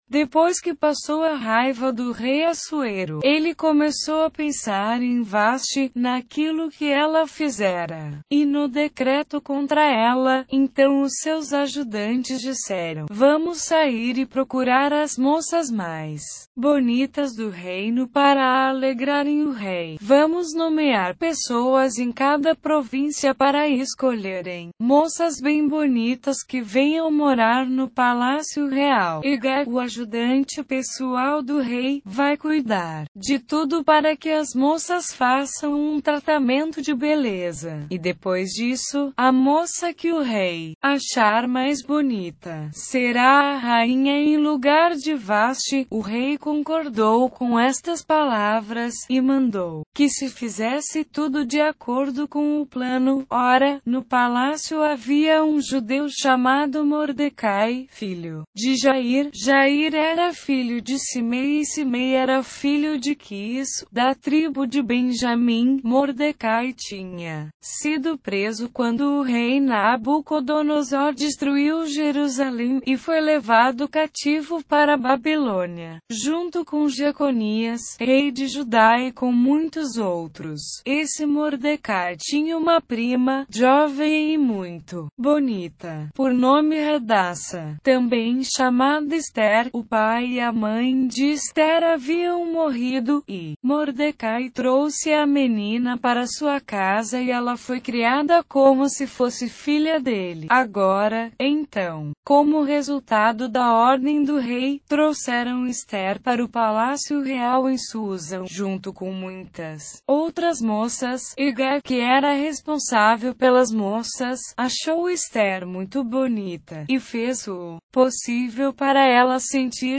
Leitura na versão Bíblia Viva - Português